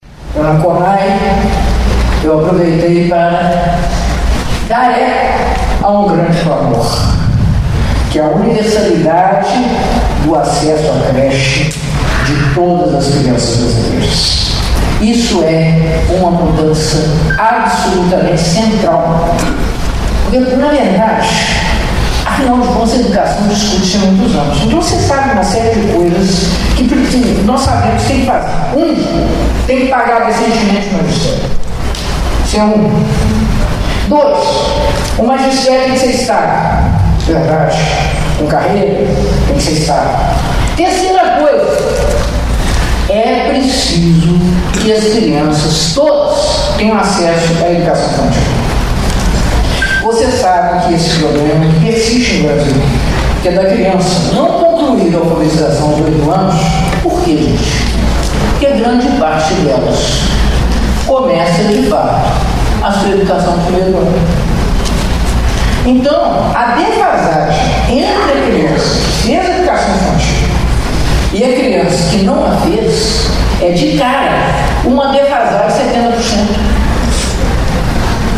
Durante a cerimônia, a prefeita Margarida Salomão ressaltou a importância da “universalidade do acesso às creches”.
Prefeita-Margarida-Salomao-Seminario-Professores-Nomeados-Juiz-de-Fora.mp3